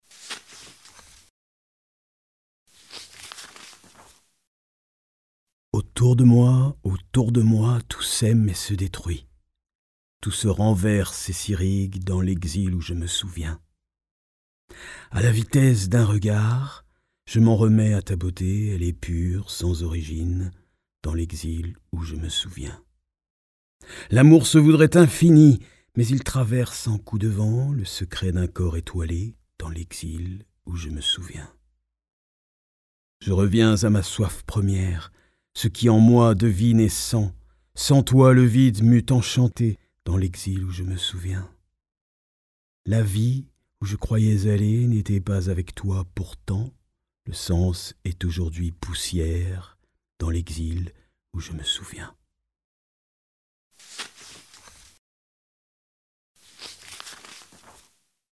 Lecture